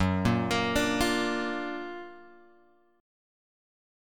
F# Augmented Major 7th